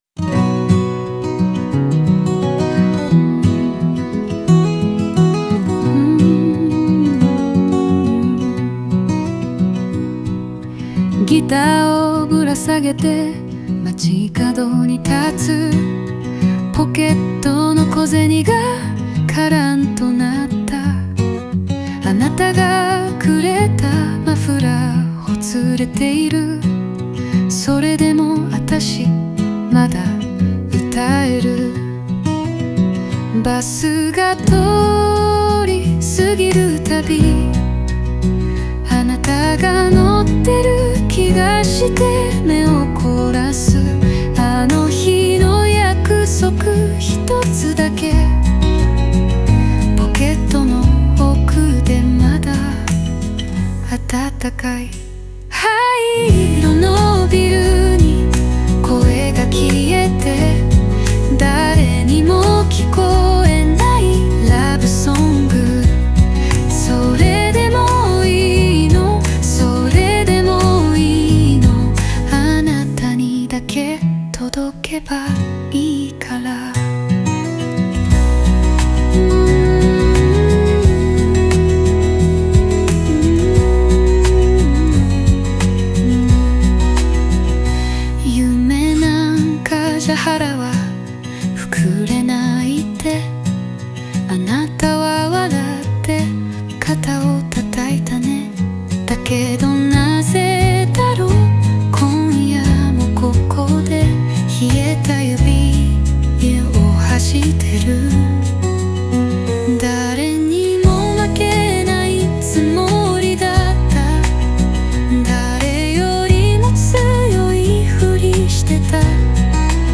昭和フォーク特有の、素朴で飾らない言葉と、胸に残る郷愁が響く一曲です。